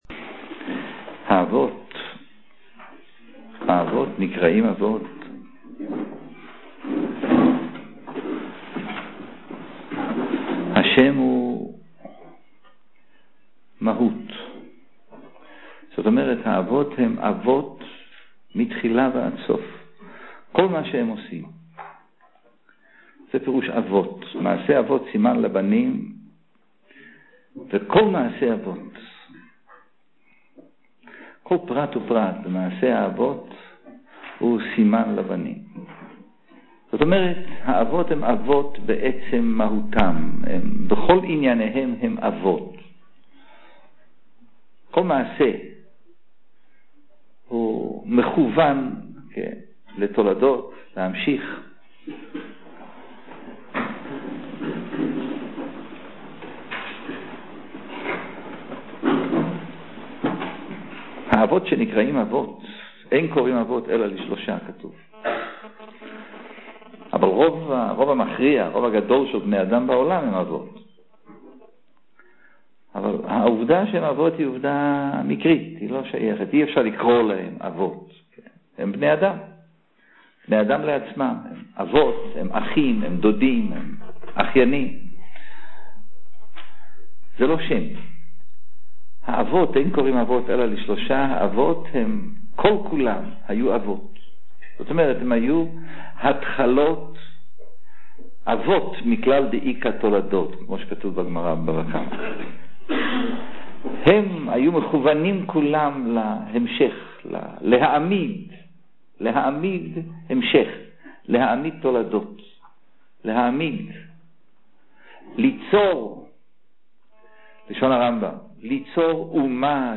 Ваеце – Урок